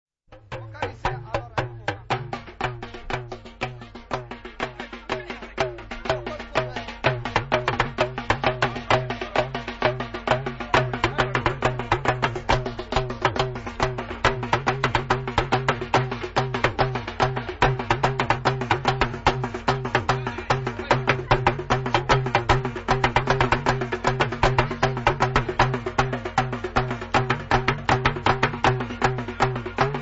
Ganga et kalangou